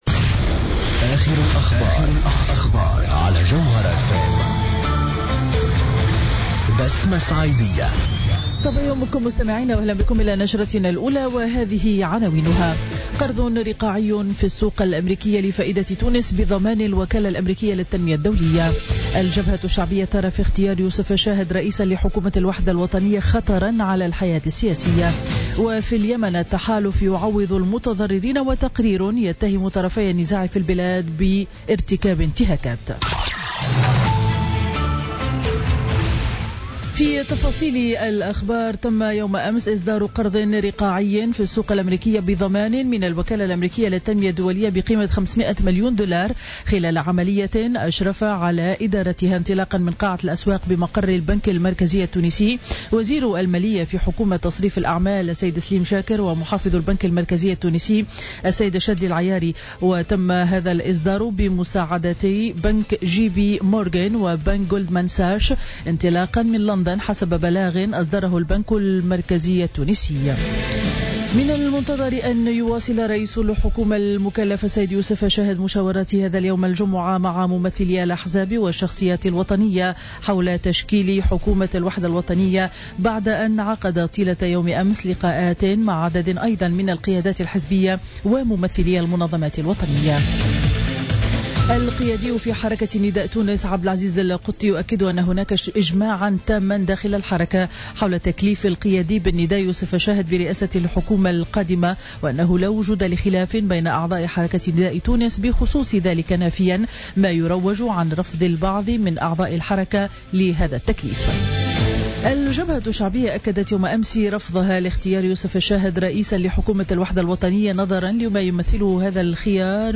Journal Info 07h00 du vendredi 5 août 2016